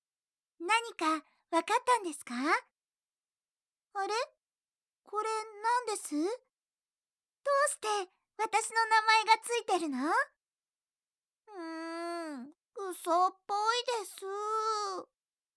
ボイスサンプル6（可愛い・女の子）[↓DOWNLOAD]
声質は少しハスキーなところがあります。